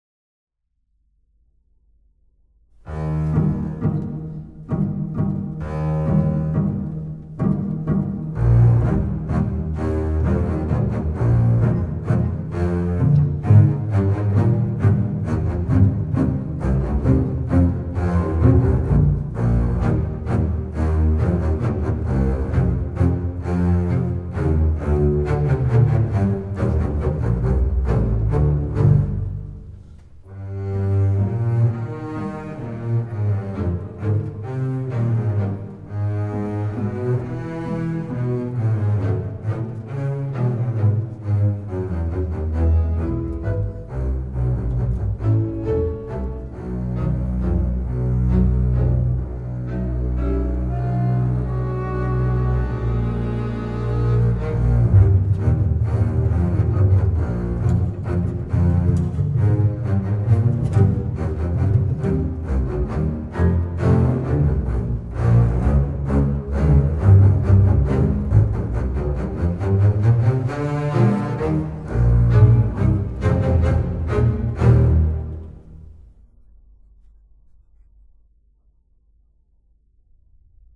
Genre: Double Bass